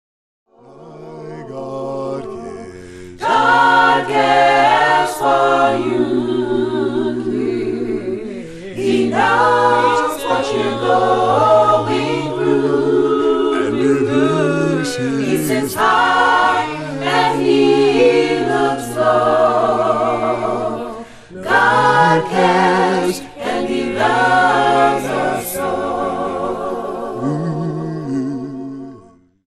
Your source for the best in A'cappella Christian Vusic ®